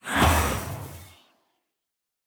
Minecraft Version Minecraft Version snapshot Latest Release | Latest Snapshot snapshot / assets / minecraft / sounds / block / trial_spawner / spawn2.ogg Compare With Compare With Latest Release | Latest Snapshot
spawn2.ogg